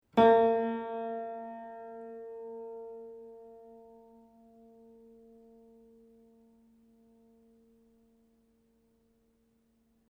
I campioni sonori che seguono sono stati registrati subito dopo il montaggio dei martelli (originali e replicati), sulle rispettive meccaniche (ottobre 2002): il suono dei martelli originali (nei quali le pelli sono solcate e un po' consunte alla sommità), è come facilmente prevedibile leggermente più pungente, di quello dei martelli replicati, al momento della registrazione intatti e del tutto privi di solchi.
Ascolta LA # 2 (martello originale), primo livello di sollecitazione meccanica